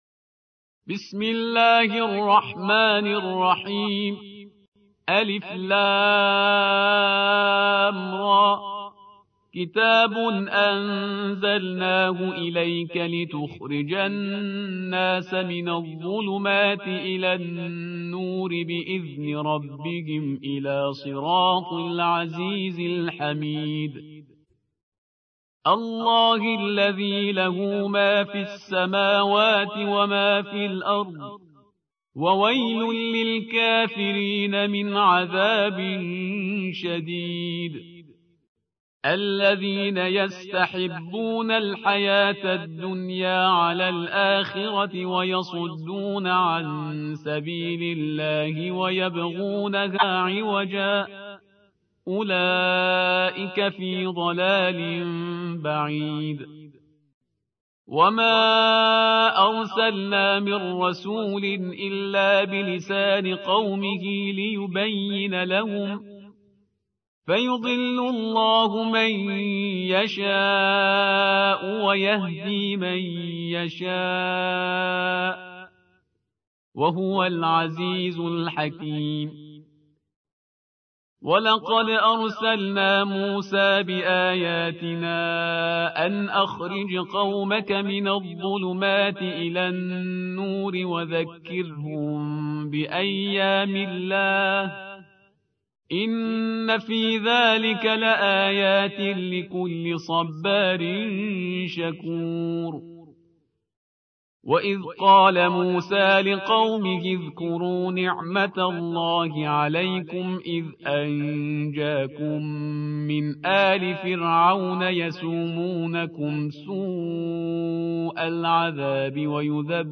14. سورة إبراهيم / القارئ